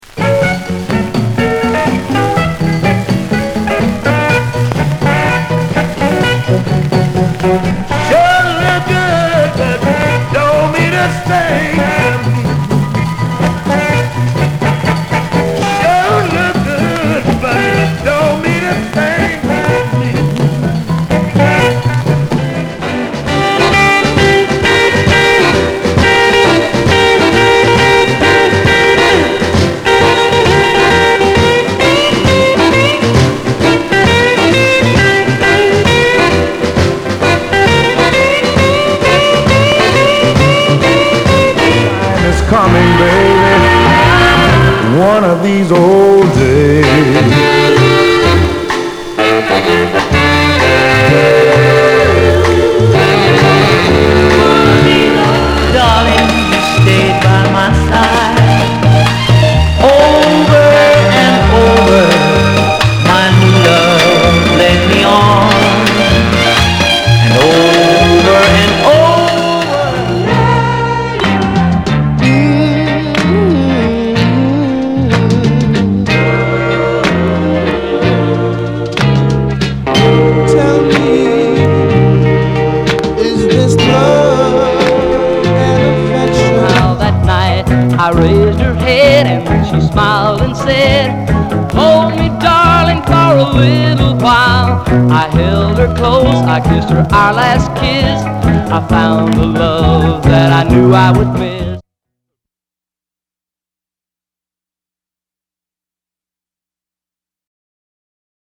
R&B、ソウル
/盤質/両面やや傷あり/US PRESS